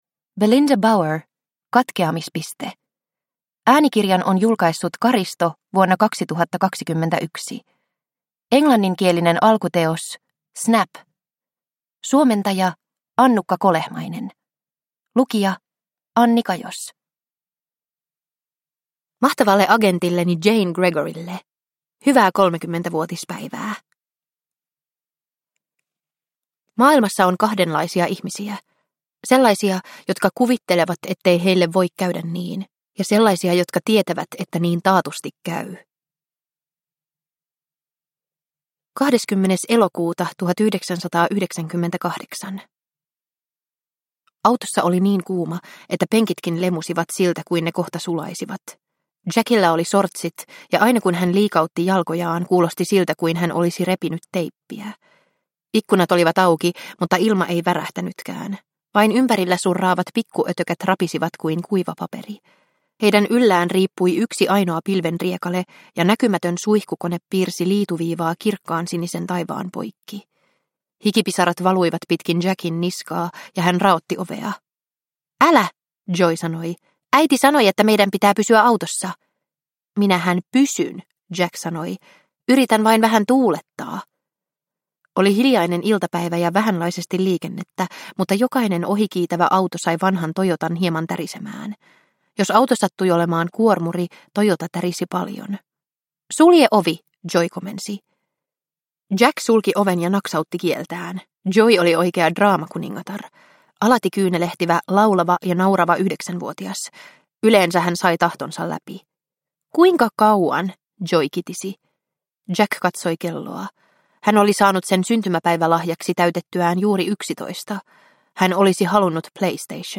Katkeamispiste – Ljudbok – Laddas ner